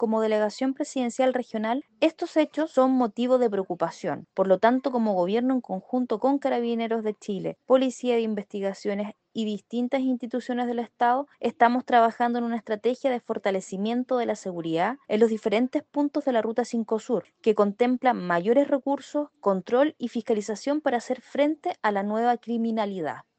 En tanto, la Delegada Presidencial Regional de Los Lagos, Paulina Muñoz, indicó que están trabajando en una estrategia para fortalecer la seguridad en distintas rutas.